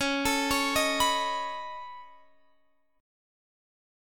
Db7sus2#5 Chord
Listen to Db7sus2#5 strummed